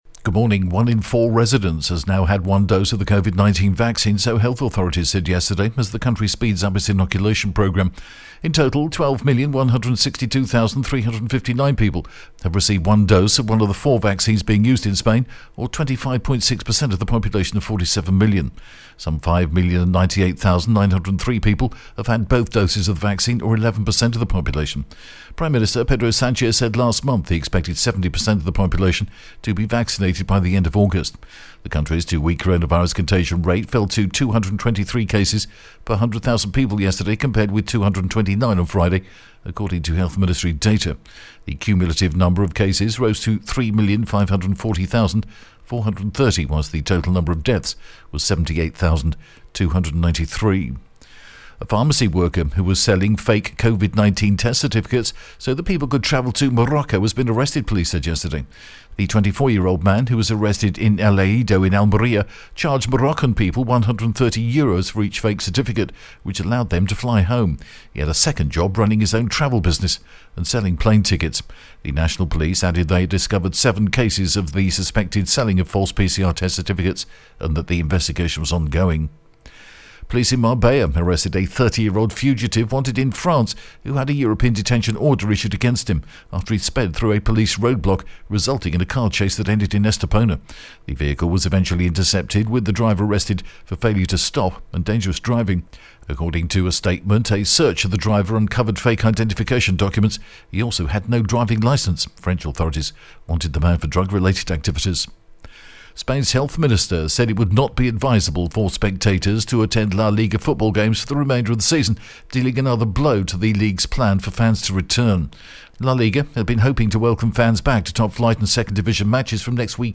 The latest Spanish news headlines in English: 4th May 2021 AM